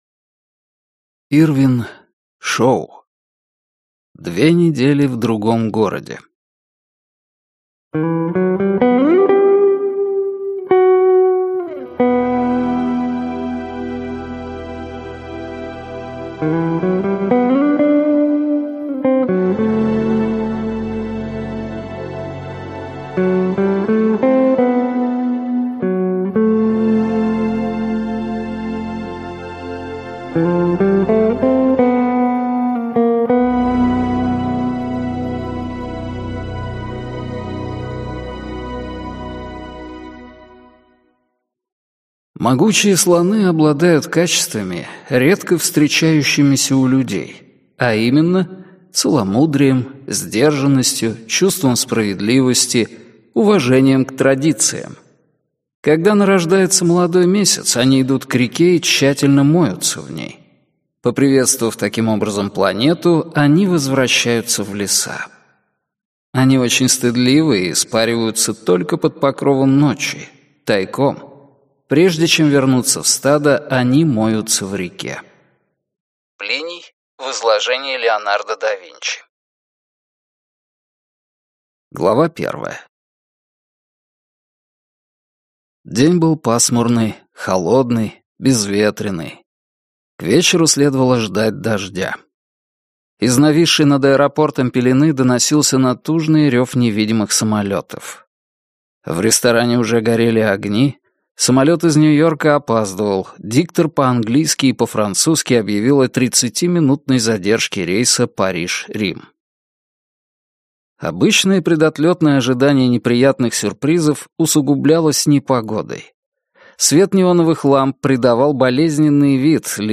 Аудиокнига Две недели в другом городе | Библиотека аудиокниг